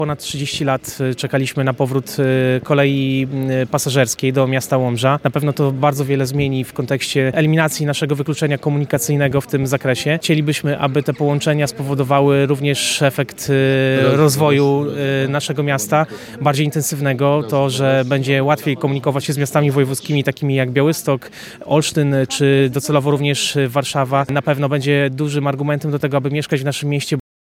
Na pozytywne skutki dla miasta zwrócił uwagę wiceprezydent Łomży Piotr Serdyński.